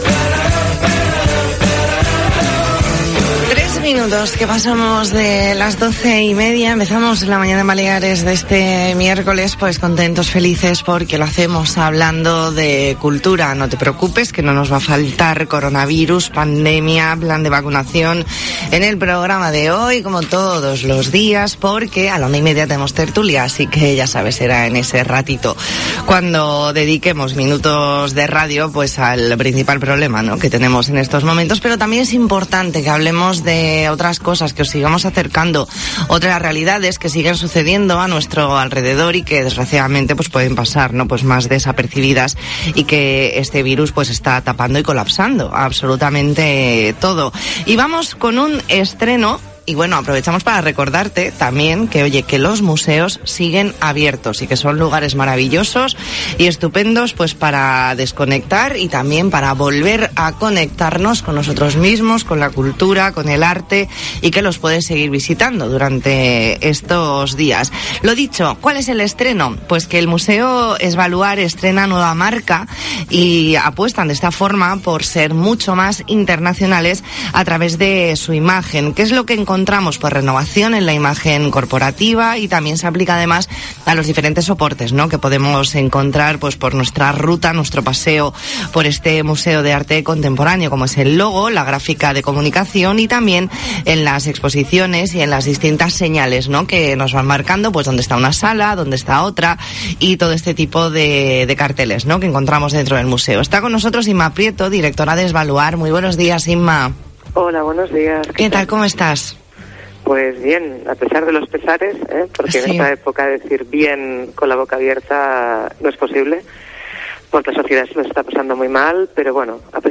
Entrevista en La Mañana en COPE Más Mallorca, miércoles 10 de febrero de 2021.